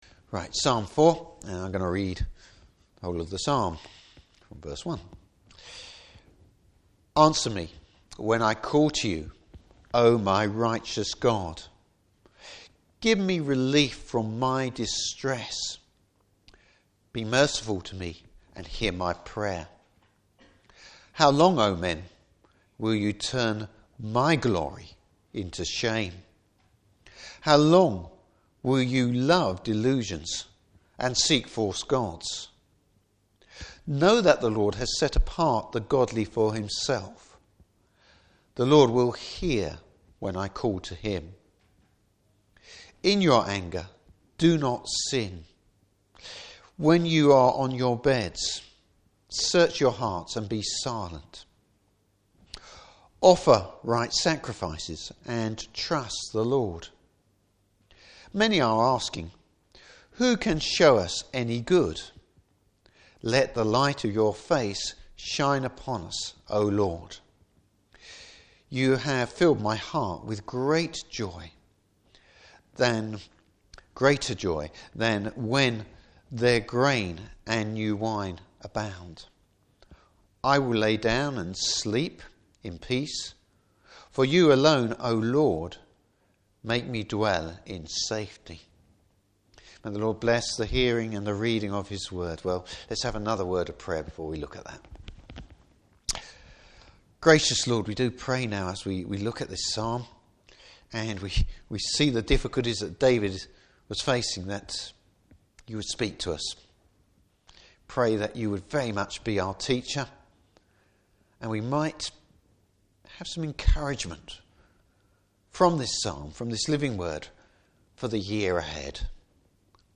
Series: New Years Sermon.
Service Type: Evening Service How avoiding the norm can be the right thing to do.